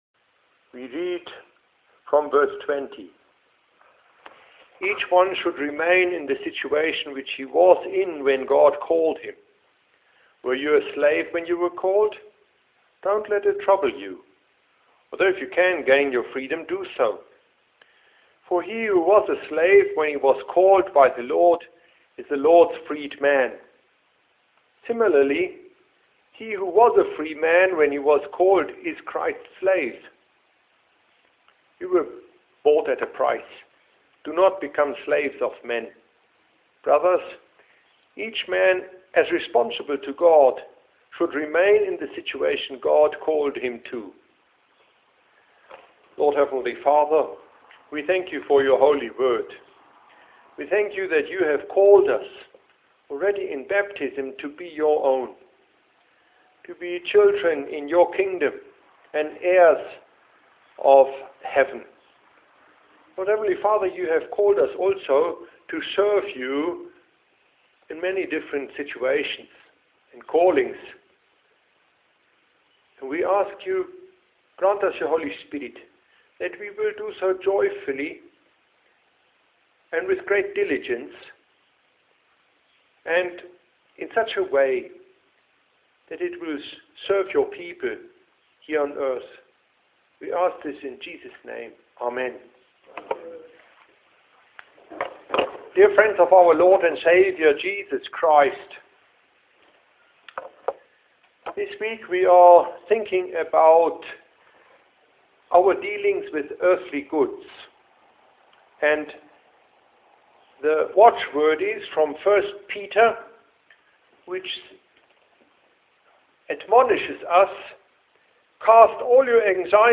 Confessional address
Here's this weeks confessional address from the Lutheran Theological Seminary in Tshwane on 1.Cor.7:20-24: